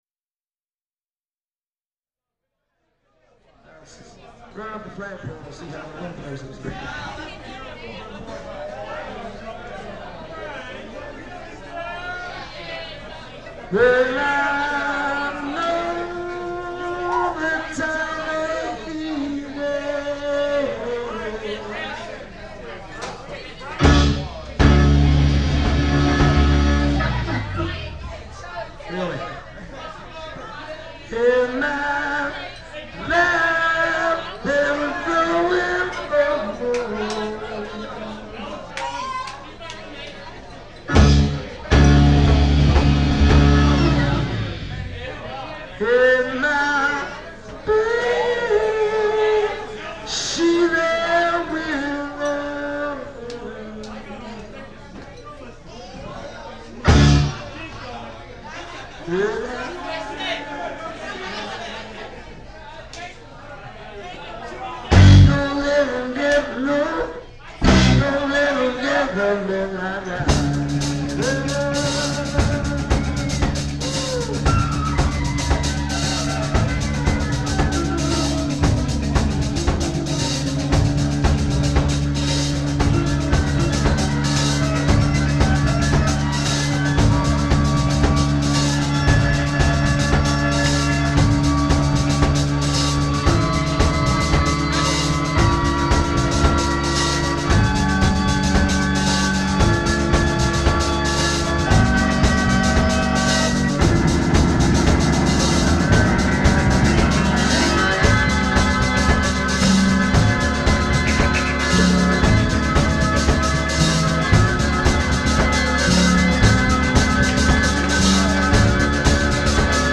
keyboards/vocals
bass/vocals
drums/vocals
guitar/vocals
tight three, four and sometimes five part harmonies